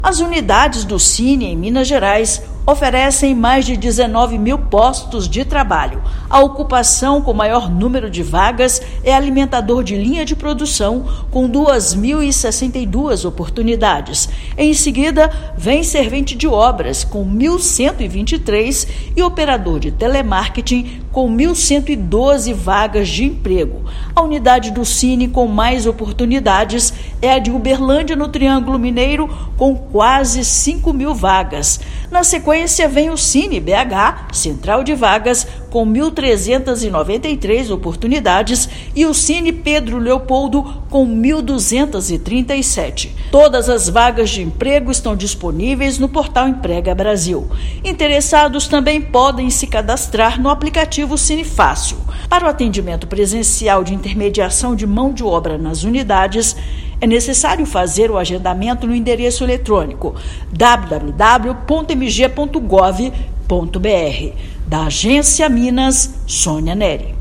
Interessados podem consultar as oportunidades disponíveis no Portal Emprega Brasil. Ouça matéria de rádio.